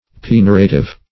Pignorative \Pig"no*ra*tive\, a.